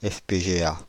Ääntäminen
Ääntäminen France (Île-de-France): IPA: /ɛf.pe.ʒe.a/ Haettu sana löytyi näillä lähdekielillä: ranska Käännös Konteksti Substantiivit 1.